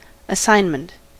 assignment: Wikimedia Commons US English Pronunciations
En-us-assignment.WAV